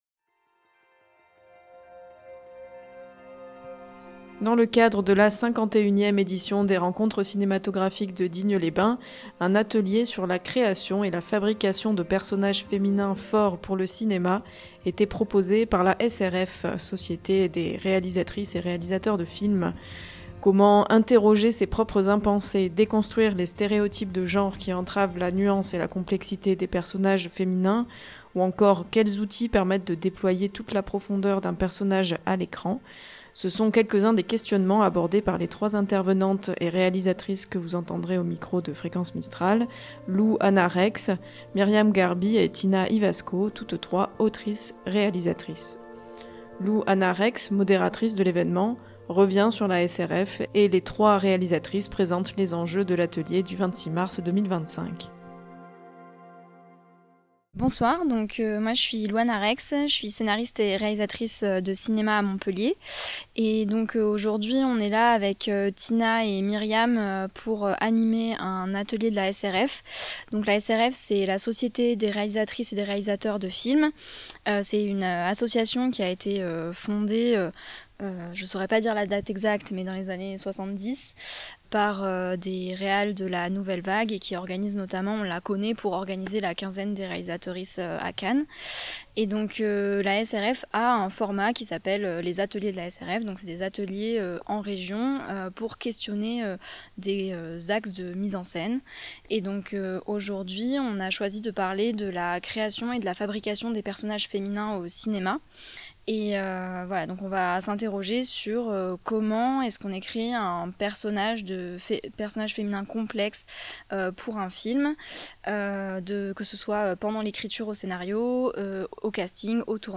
Cet Atelier SRF explore ces enjeux en compagnie de trois réalisatrices